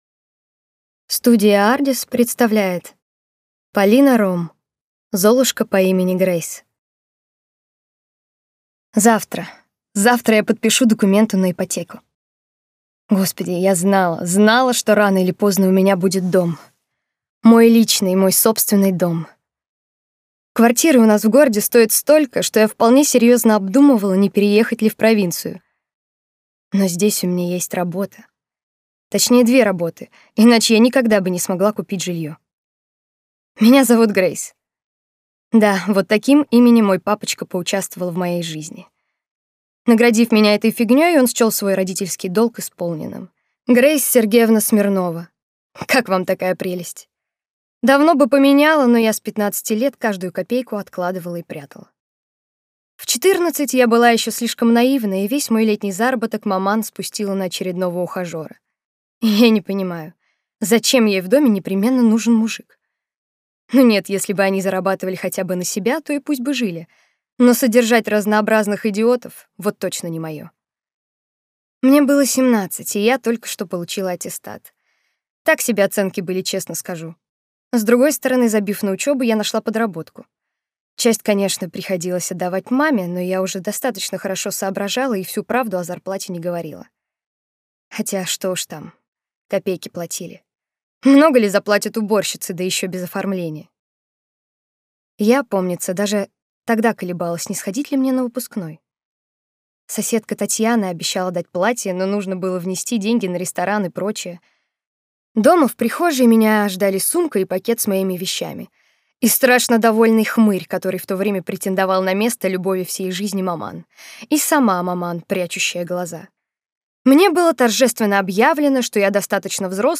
Аудиокнига Золушка по имени Грейс | Библиотека аудиокниг